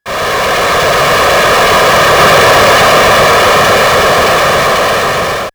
GasReleasing16.wav